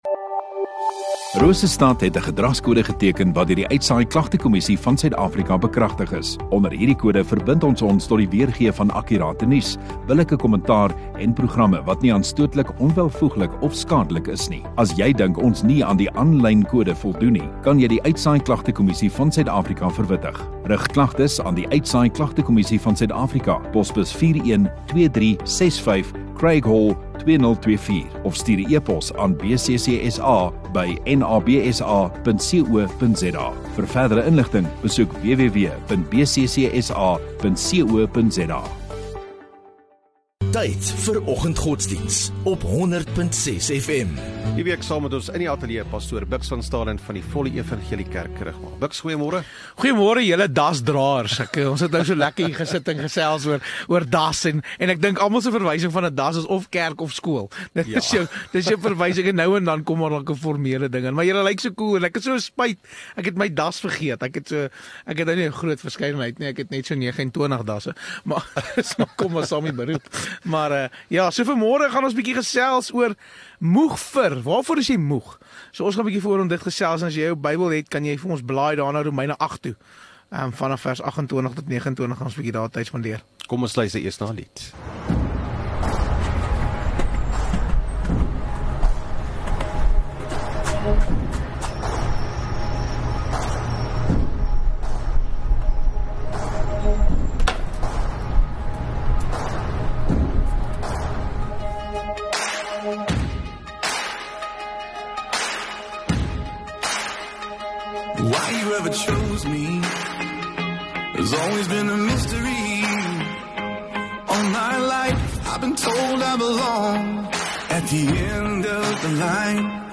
9 Oct Woensdag Oggenddiens